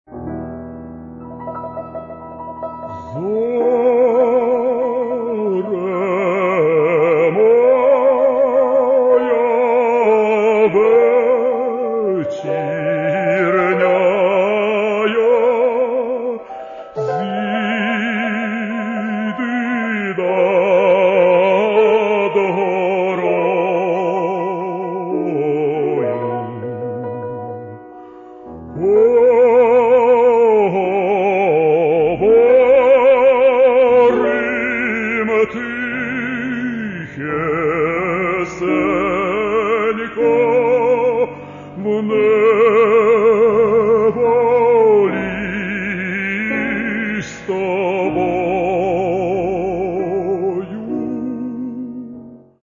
Українські солоспіви